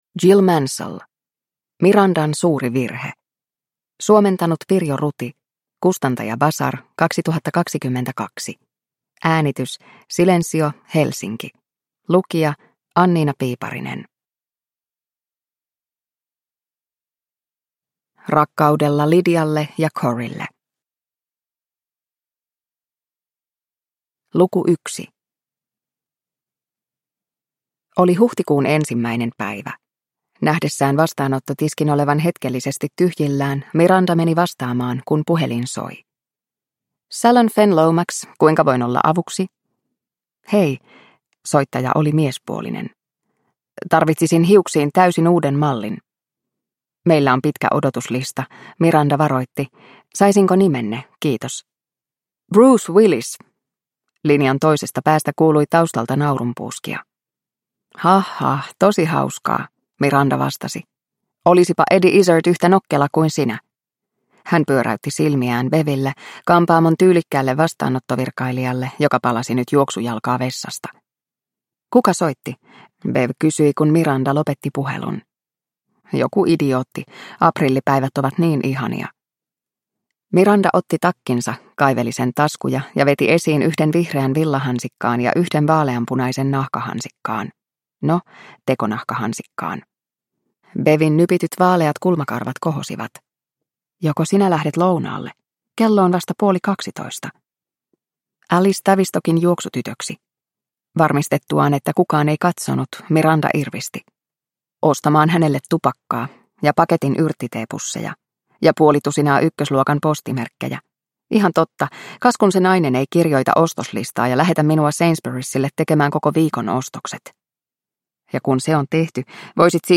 Mirandan suuri virhe – Ljudbok – Laddas ner